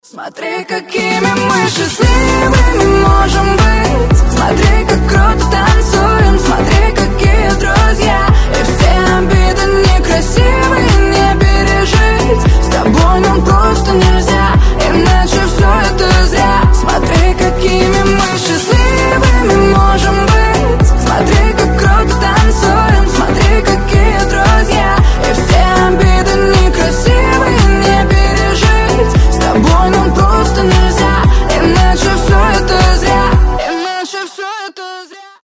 • Качество: 320, Stereo
позитивные